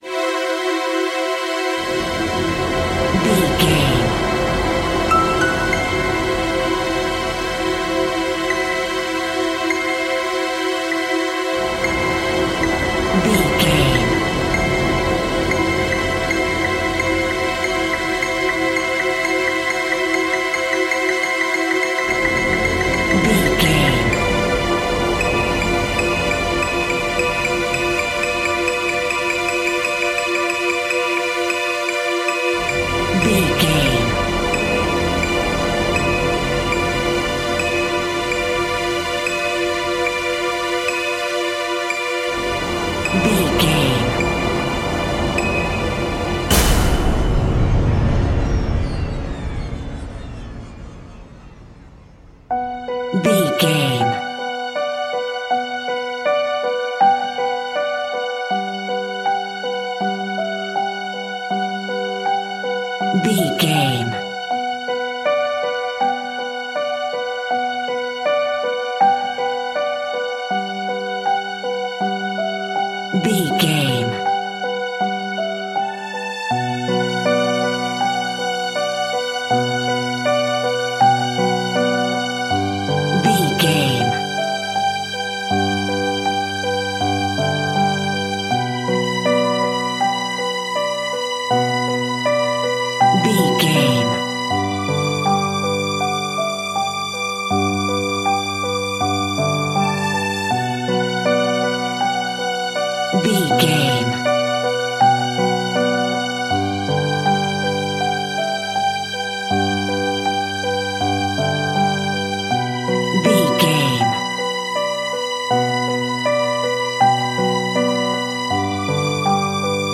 In-crescendo
Thriller
Aeolian/Minor
scary
ominous
haunting
eerie
Horror Synths
horror piano
Scary Strings